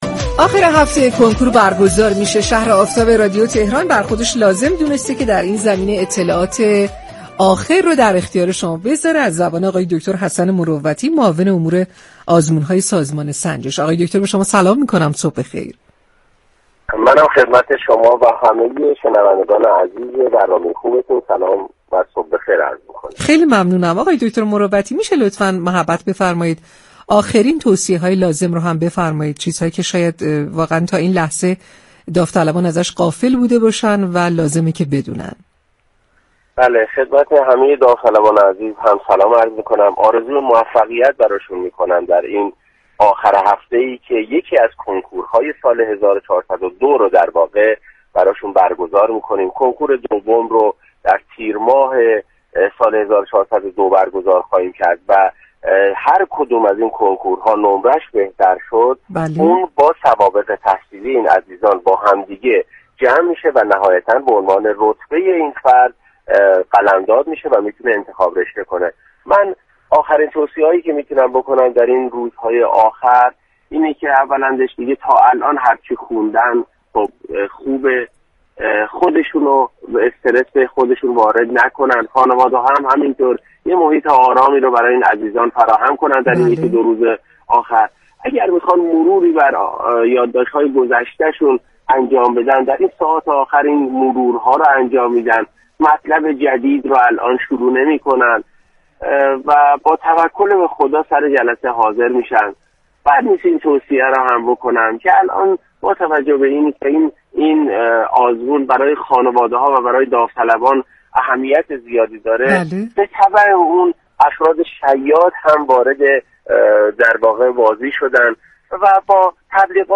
به گزارش پایگاه اطلاع رسانی رادیو تهران، حسن مروتی معاون امور آزمون‌های سازمان سنجش در گفت و گو با «شهر آفتاب» رادیو تهران گفت: مرحله اول كنكور سال1402 در روزهای29 و 30 دی‌ماه برگزار می‌شود.